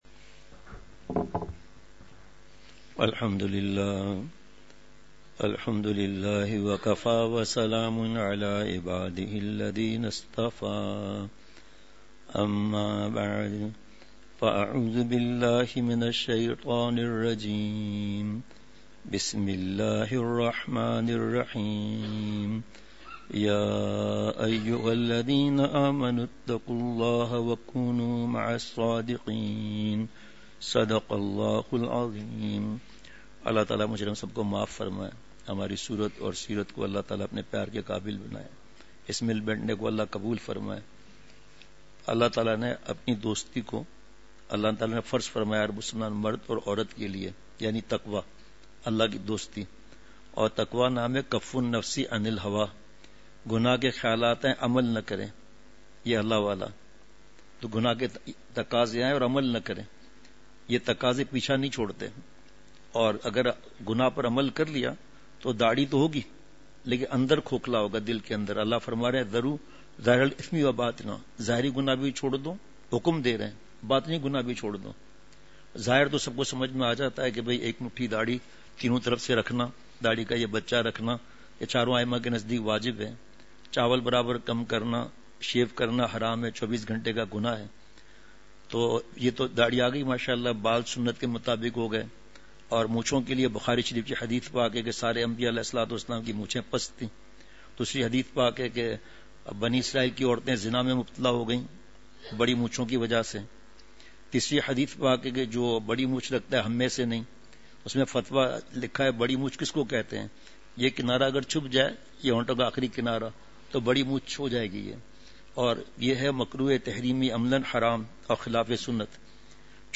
عشاء مجلس ۲۴ جنوری ۲۶ء:اللہ سے دوستی کا راستہ:تقویٰ، توبہ !
*مقام:مسجد اختر نزد سندھ بلوچ سوسائٹی گلستانِ جوہر کراچی*